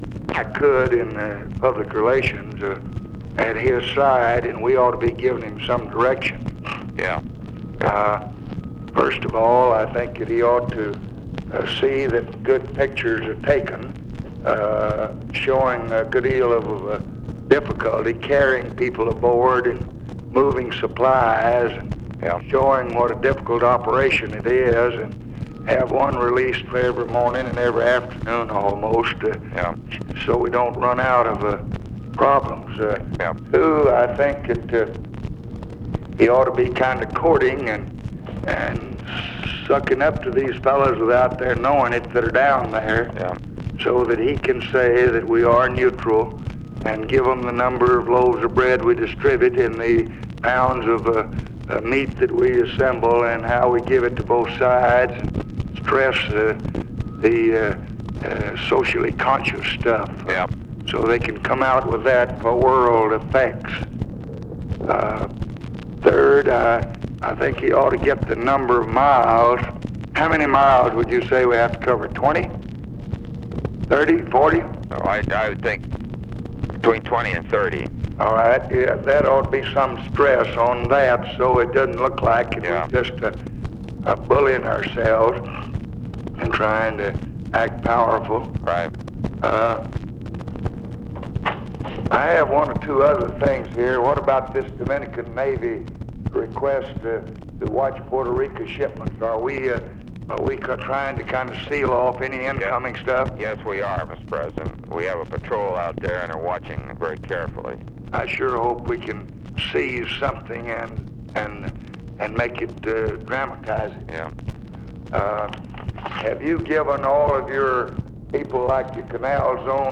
Conversation with ROBERT MCNAMARA, May 1, 1965
Secret White House Tapes